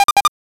NOTIFICATION_8bit_01_mono.wav